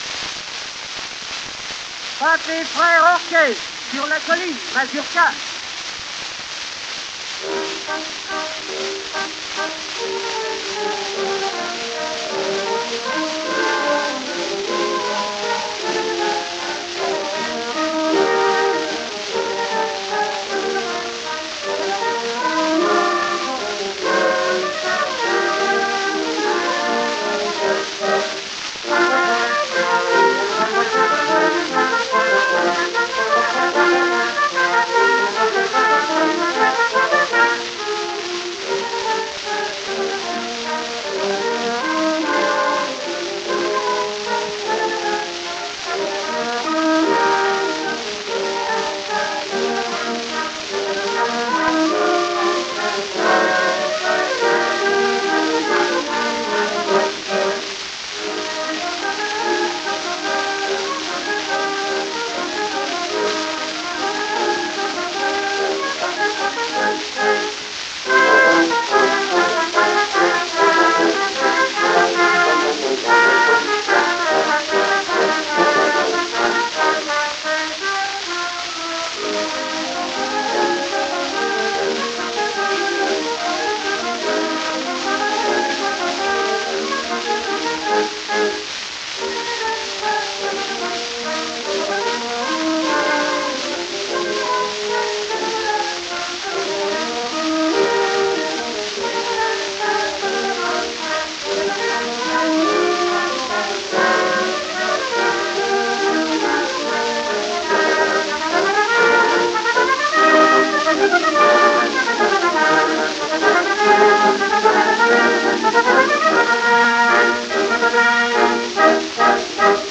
Sie spielten bei der Aufnahme typischerweise in gleichmäßig hoher Lautstärke.
Orchestre Pathé Frères: Sur la colline (E. Launay).